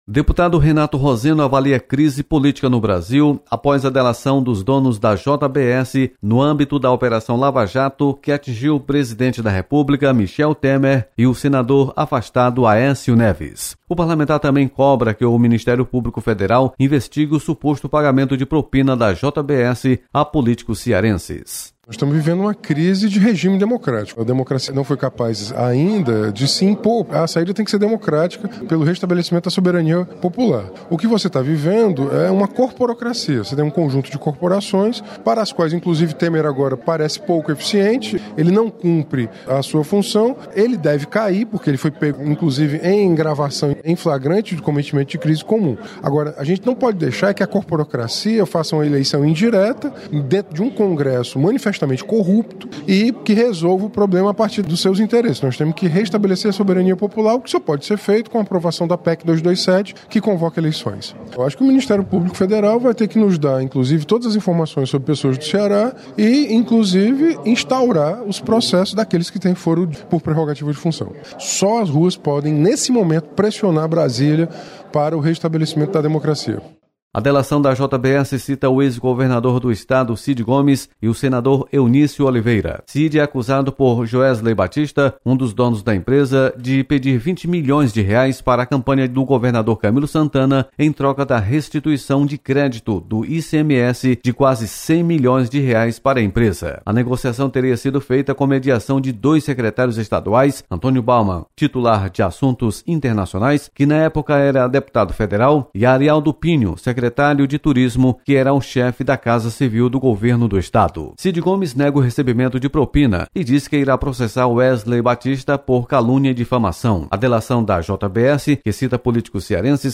Deputado Renato Roseno avalia crise política instalada no País.